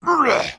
damage_2.wav